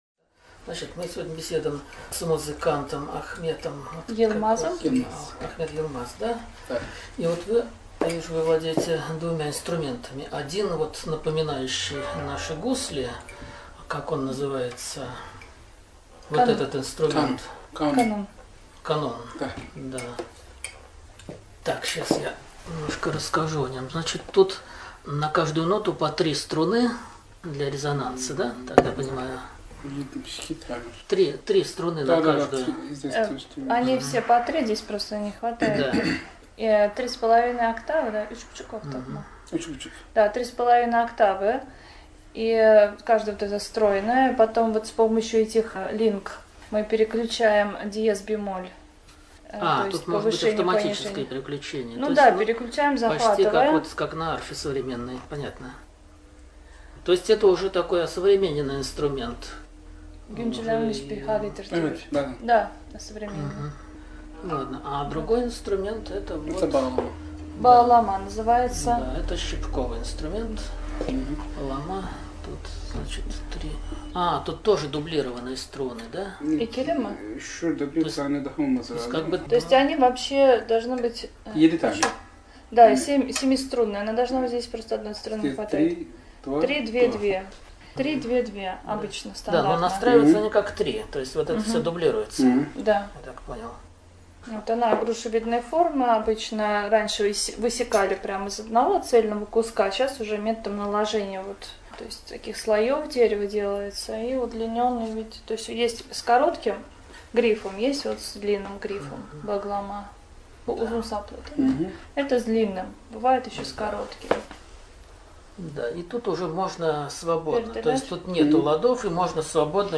Семинар, посвященный музыкальным традициям Турции, был предварением концерта фестиваля «Вселенная звука». Позднее состоялась беседа с некоторыми его участниками, где более подробно обсуждались и чисто музыкальные и религиозные аспекты турецкой культуры.